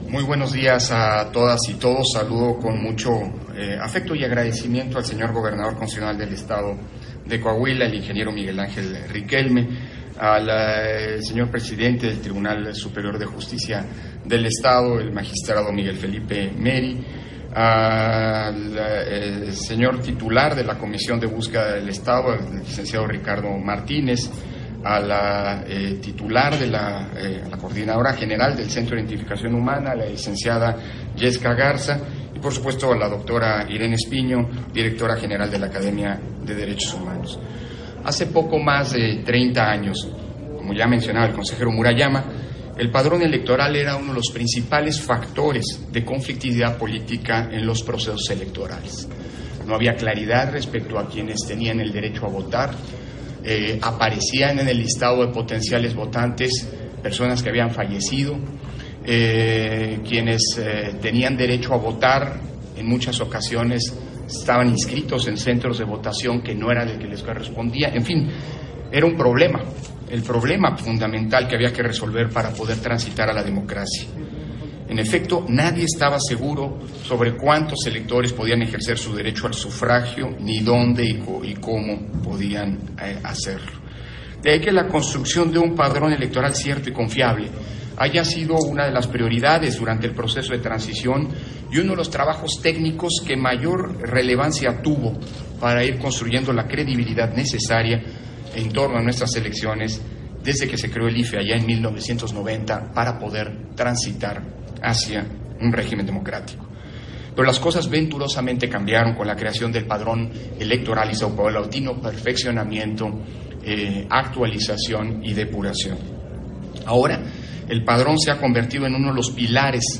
Intervención de Lorenzo Córdova, en la firma de Convenio de Apoyo y Colaboración INE-Gobierno del Estado de Coahuila, para la obtención de información en la búsqueda e identificación de personas